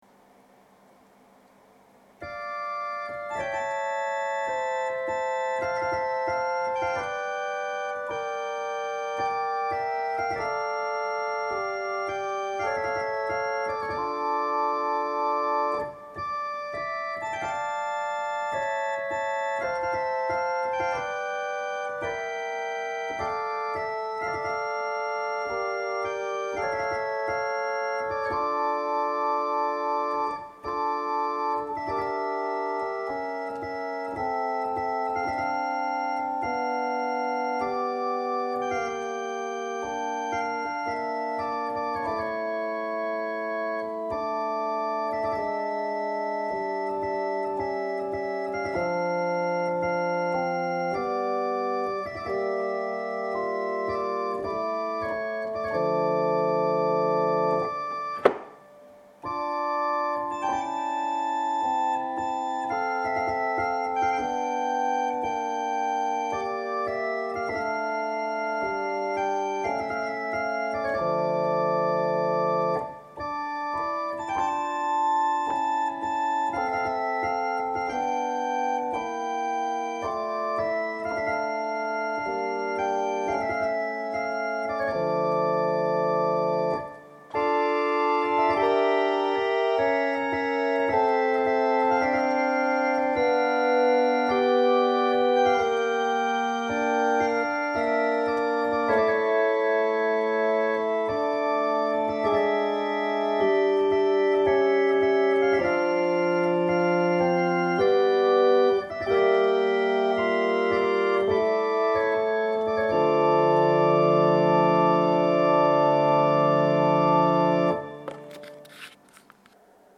聖日礼拝のご案内（待降節第2主日） – 日本基督教団 花小金井教会